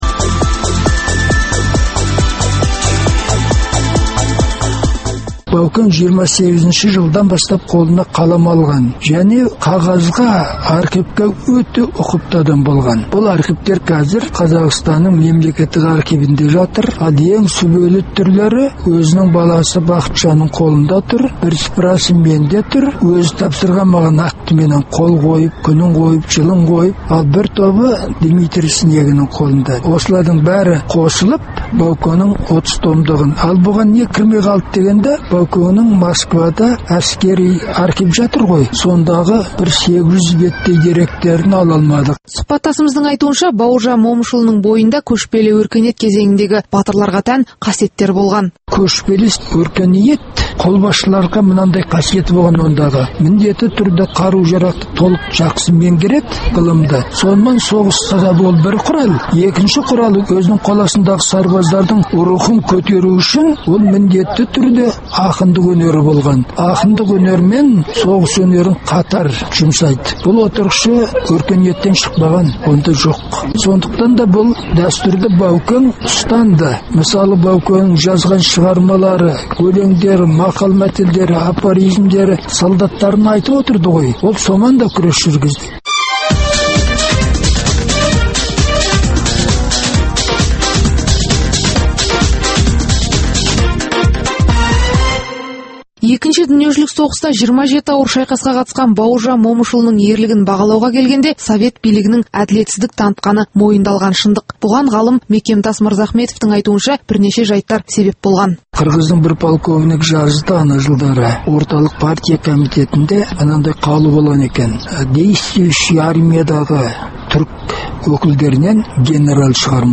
Радио хабар.